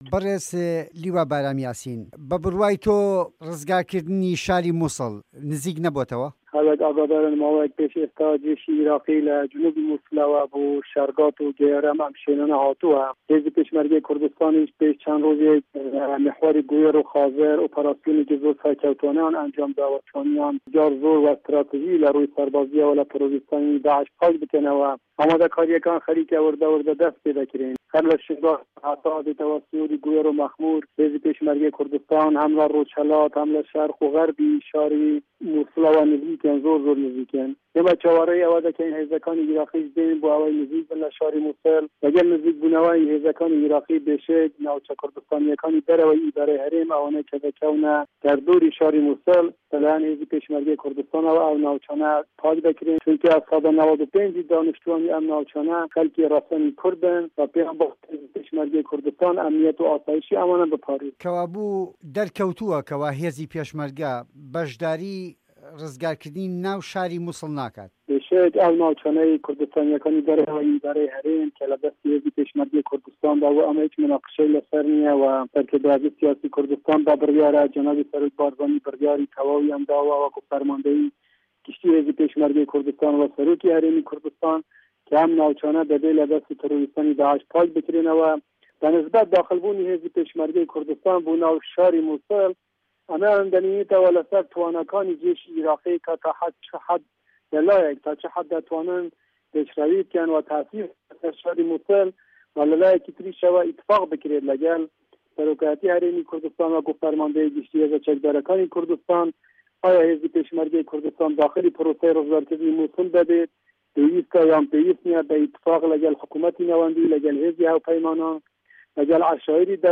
وتووێژ لەگەڵ لیوا بارام یاسین